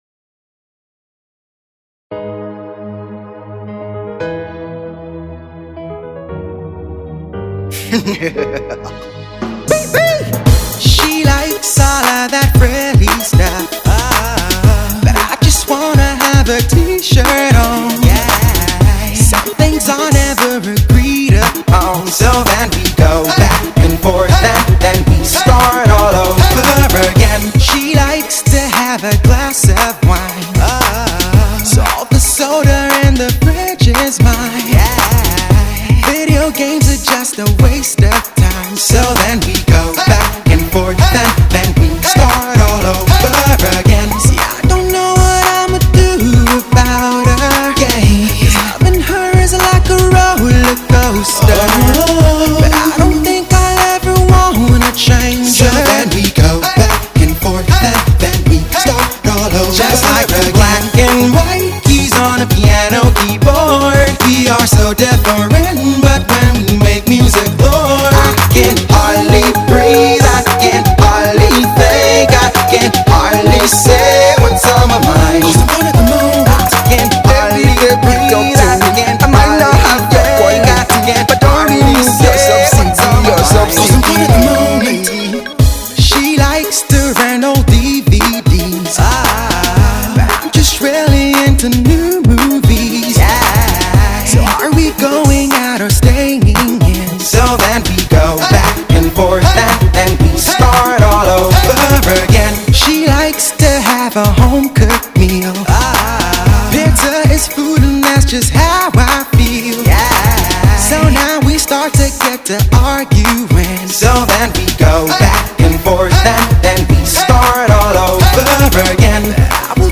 soft-voiced singer